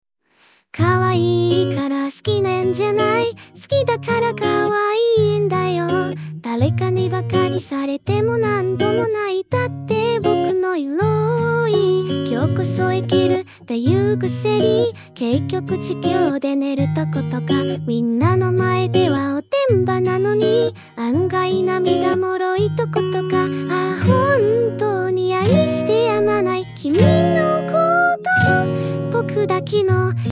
唱歌推理
唱歌：支持
女生模型模型工坊